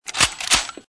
Gun Cocking